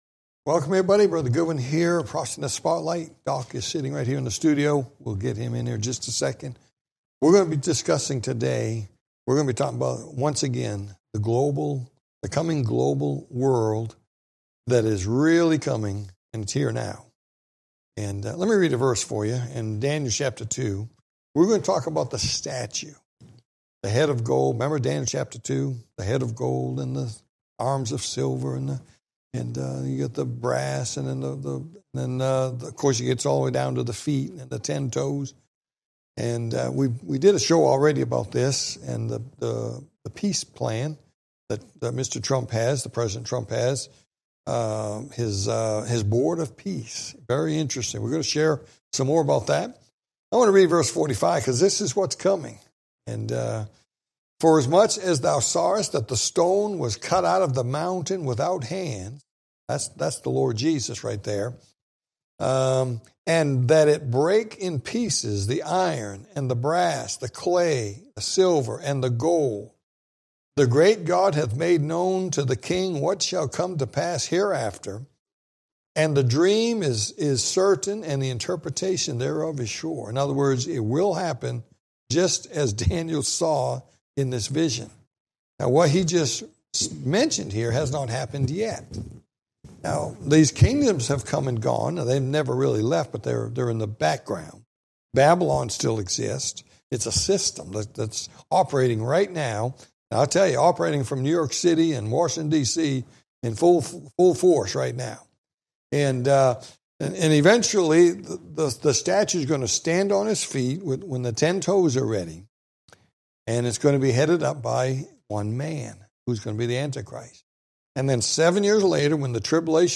Talk Show Episode, Audio Podcast, Prophecy In The Spotlight and The Coming Global Kingdom on , show guests , about The Coming Global Kingdom, categorized as History,News,Politics & Government,Religion,Society and Culture,Theory & Conspiracy